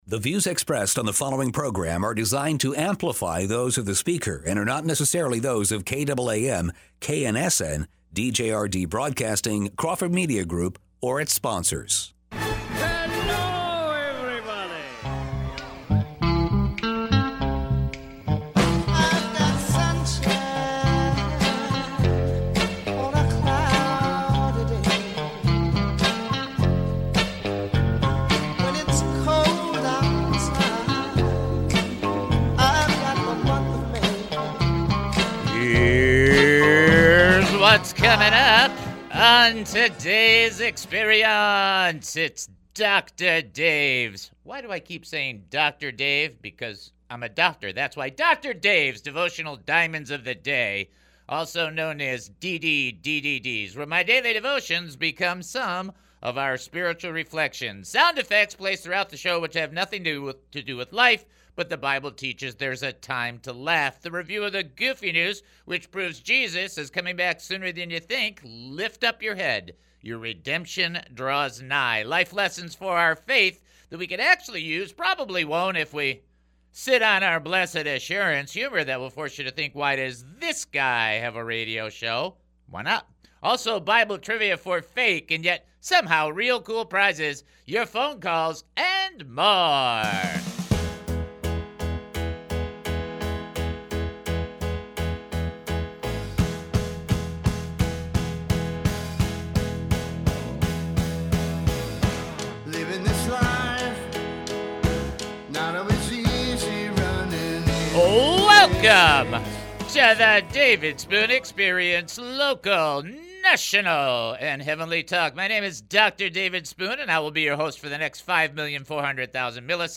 The show also includes prayers for the audience.